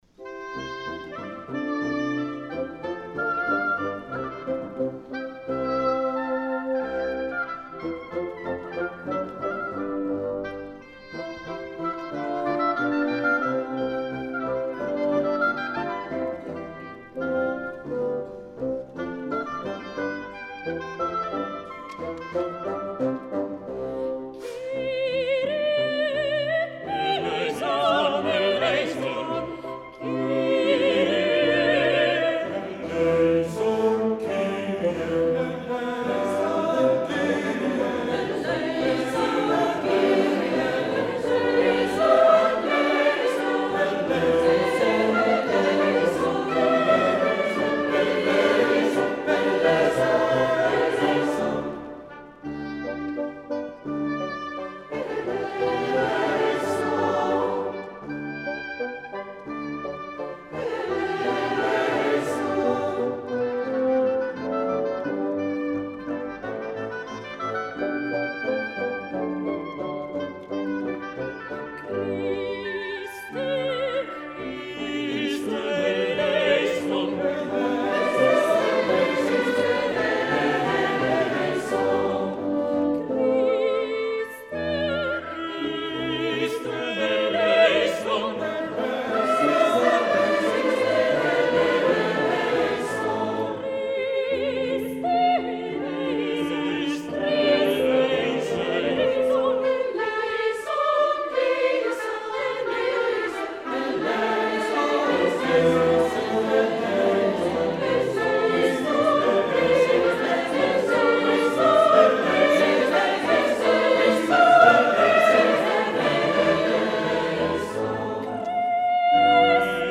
Corale Quadriclavio: Mp3 e Video
MP3 (brani eseguiti dal vivo dalla Corale Quadriclavio)